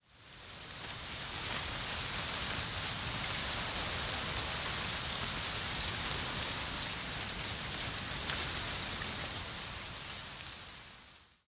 windtree_12.ogg